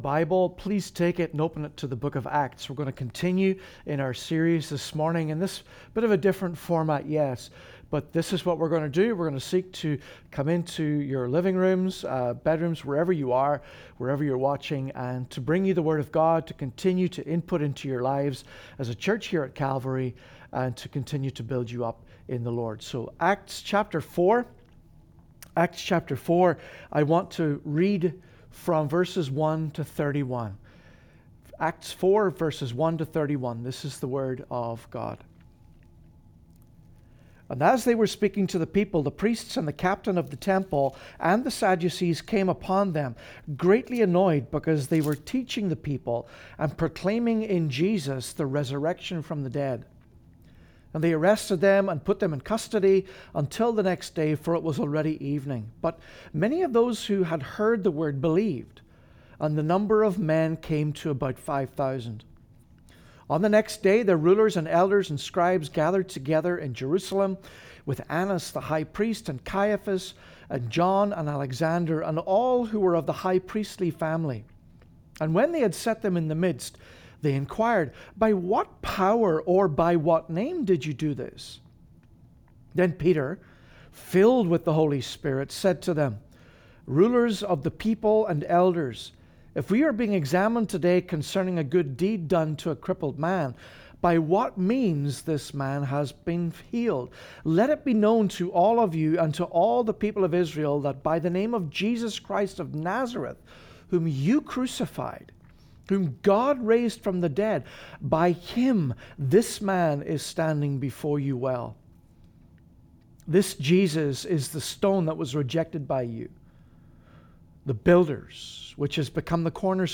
Sermon-audio.m4a